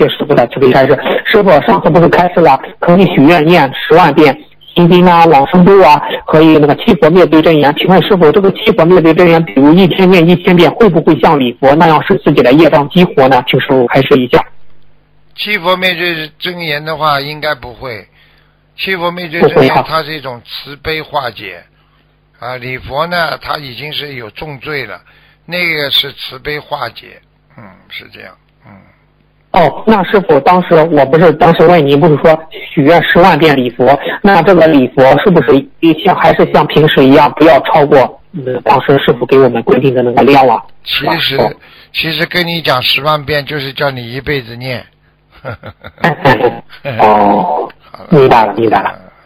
Tanya Jawab